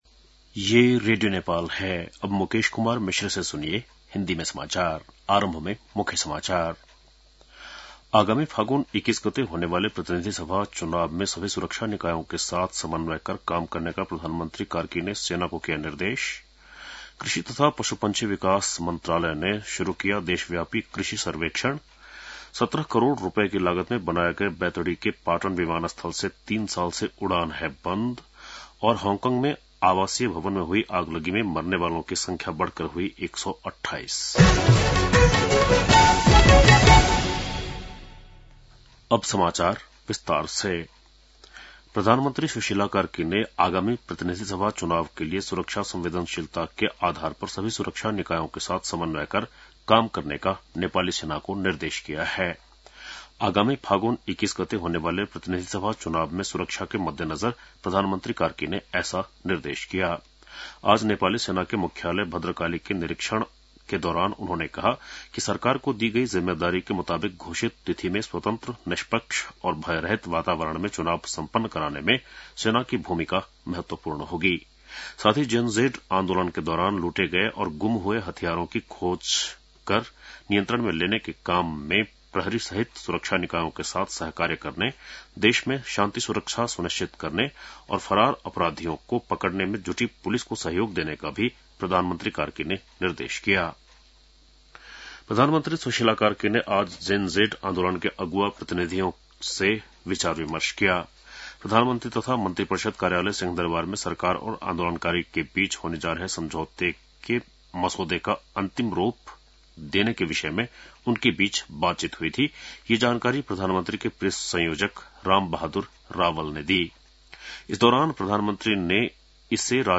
बेलुकी १० बजेको हिन्दी समाचार : १२ मंसिर , २०८२
10-pm-hindi-news-8-12.mp3